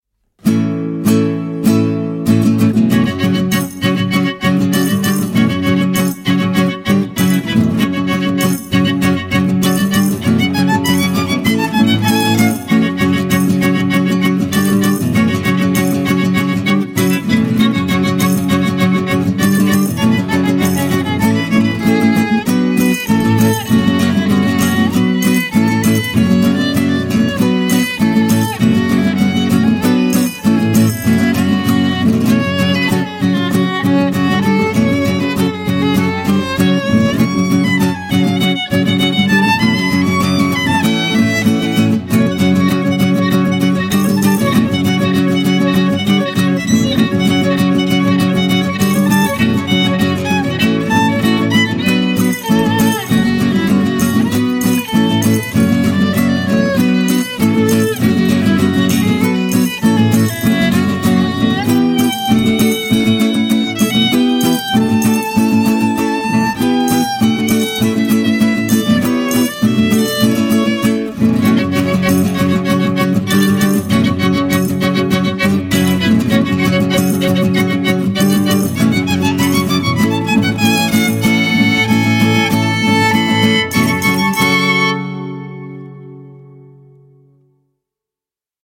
Žánr: World music/Ethno/Folk
housle
kytara